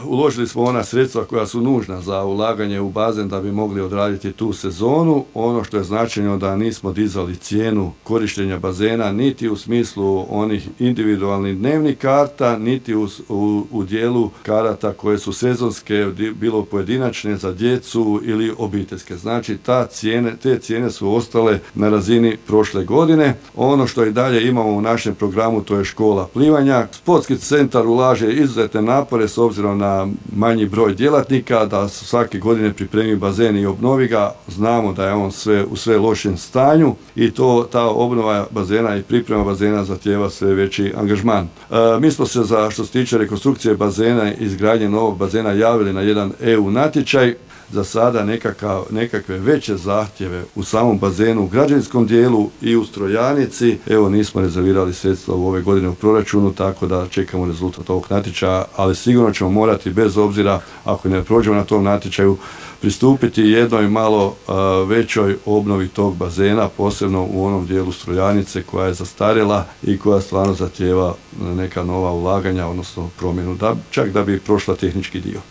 Više o tome, gradonačelnik Kutine Zlatko Babić